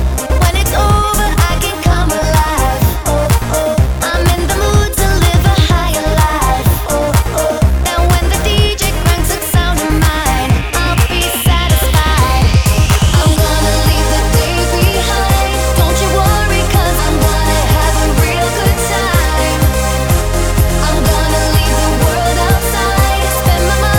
Male Parts Only R'n'B / Hip Hop 3:41 Buy £1.50